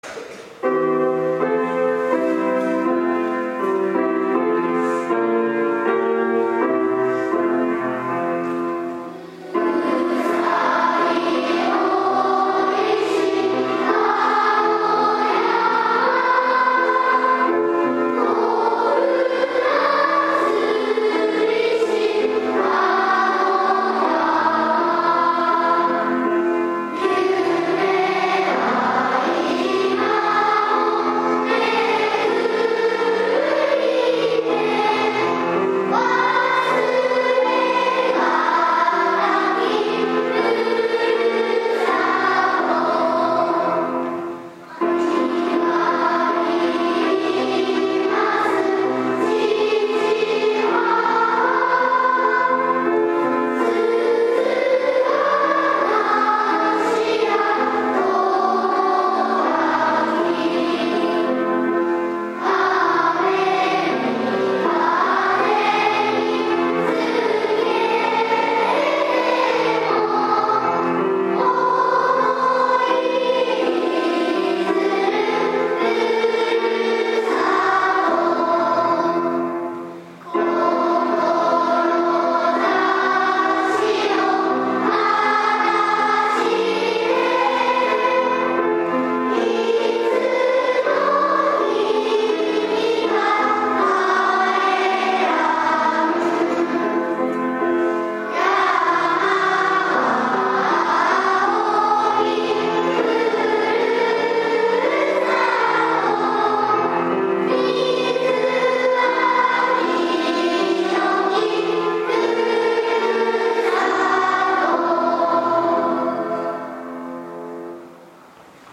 記念式典